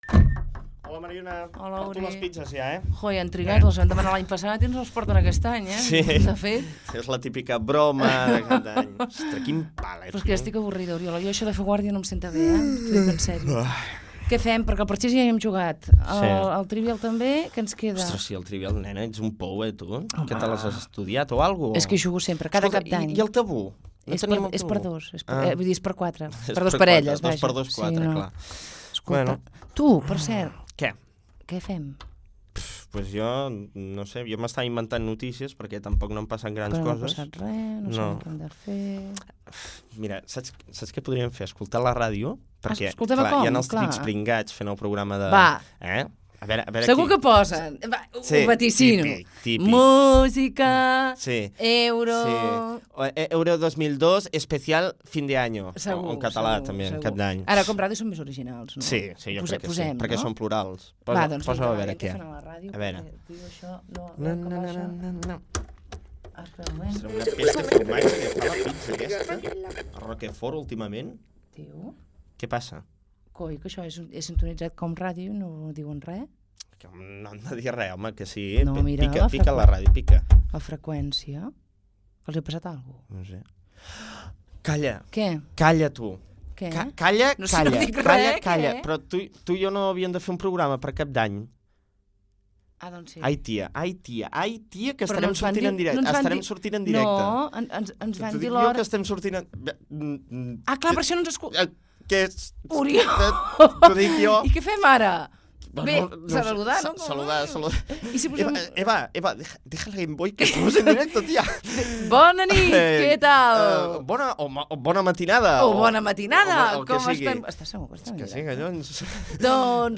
Inici del programa de cap d'any on els presentadors parlen d'Europa i de l'euro
Entreteniment